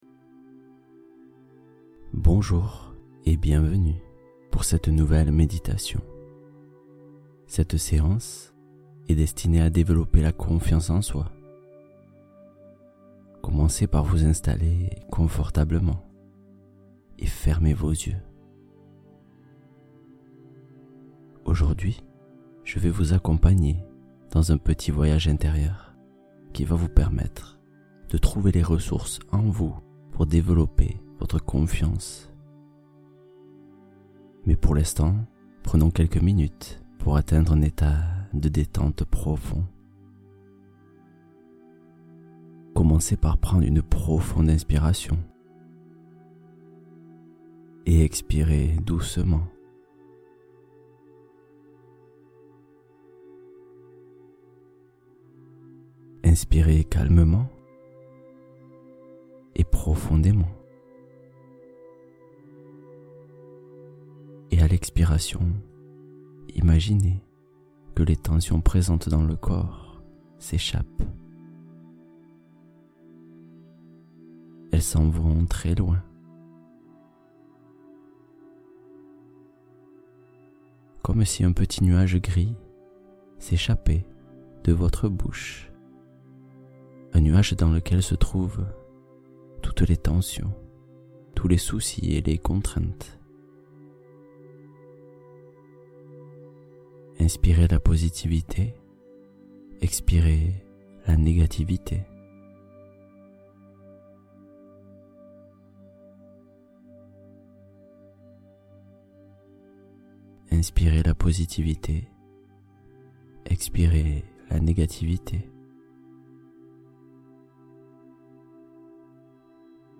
Focus Confiance : Exercice de visualisation pour renforcer son assurance